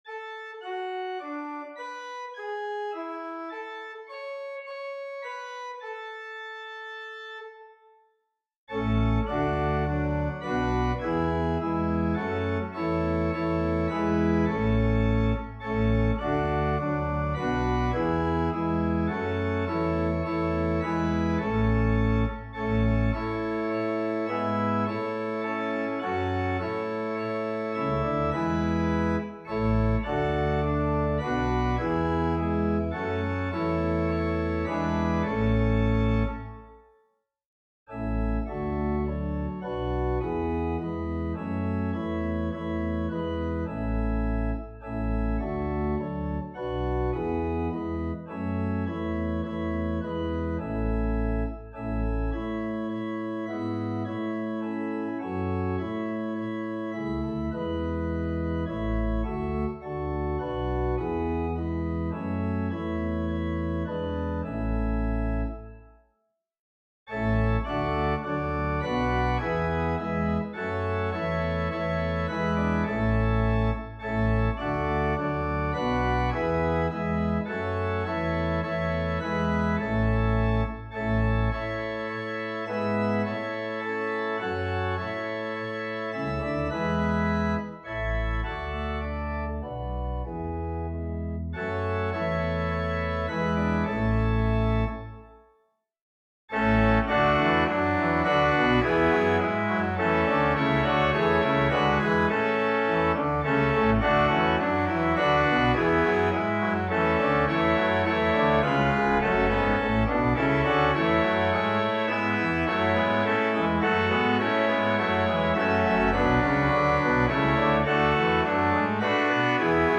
St Denio. Welsh hymn melody, in J. Robert’s Caniadau y Cyssegr, 1839
Organ: Little Waldingfield